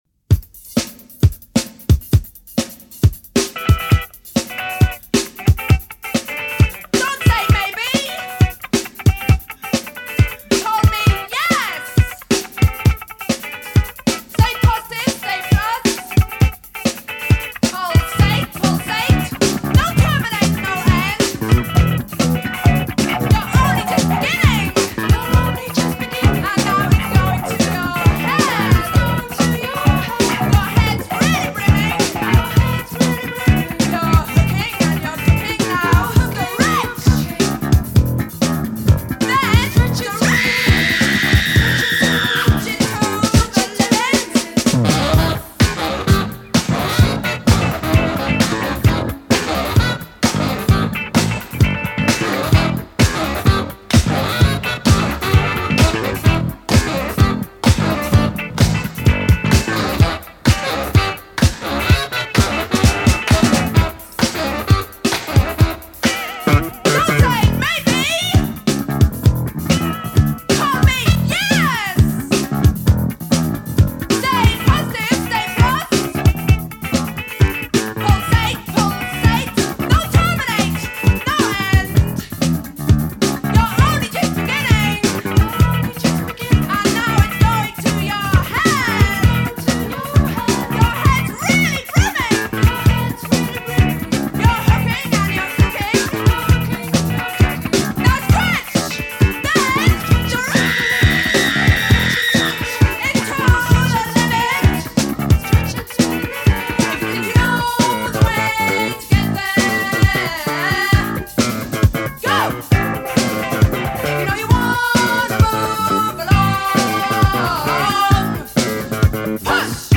has the best scream in all of music history